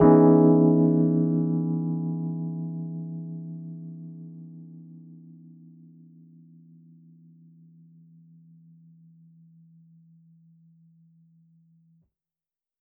JK_ElPiano3_Chord-Emaj13.wav